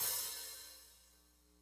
hihat02.wav